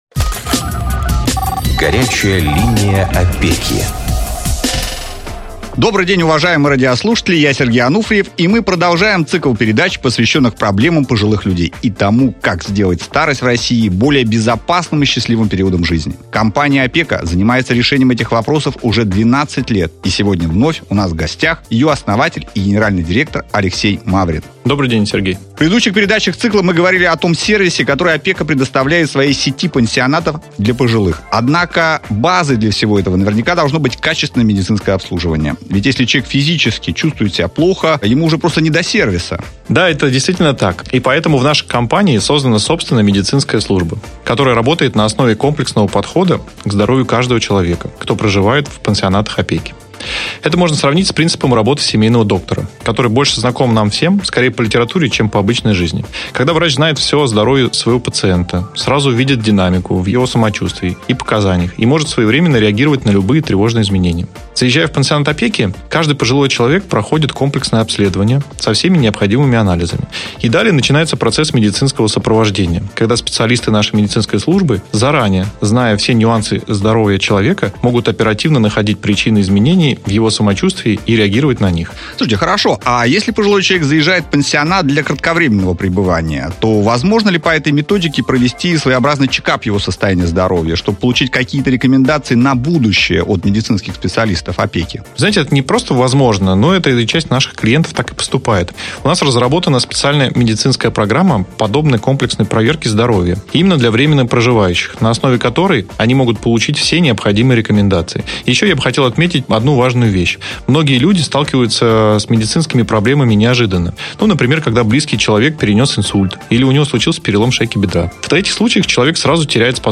Интервью